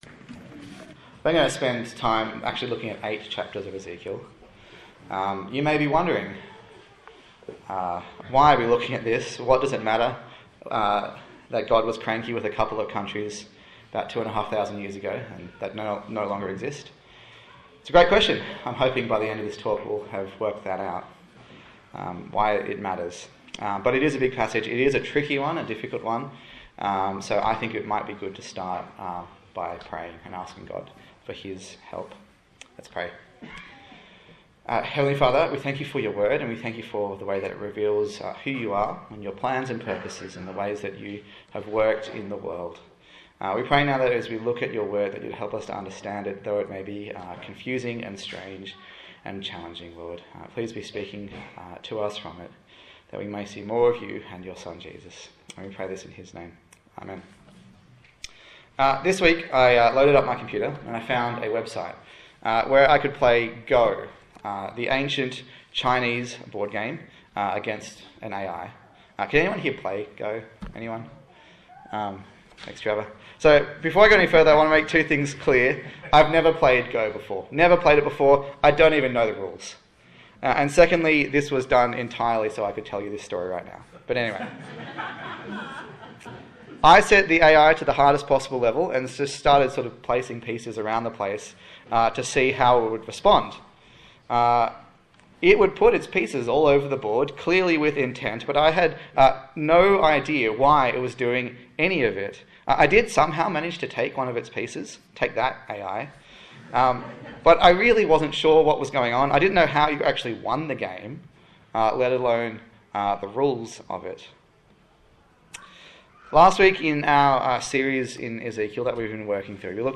Ezekiel Passage: Ezekiel 25 to 32 Service Type: Sunday Morning